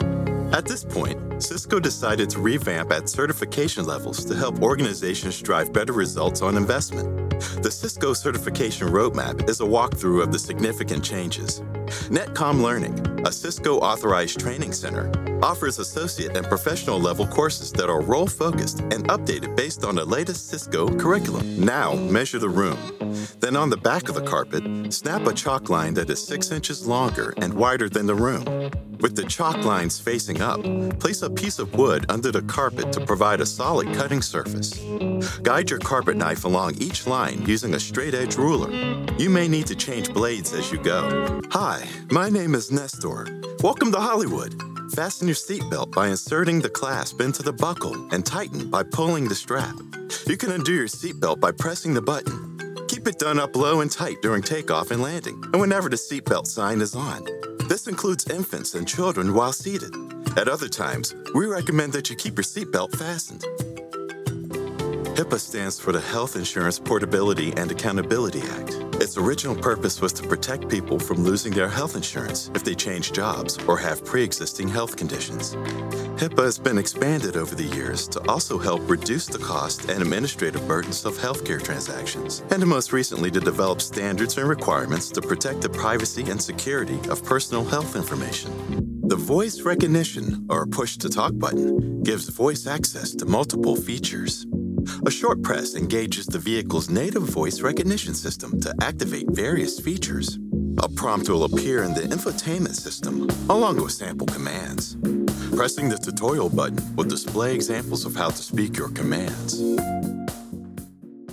Male
English (North American)
Adult (30-50), Older Sound (50+)
Main Demo
Commercial Demo
Male Voice Over Talent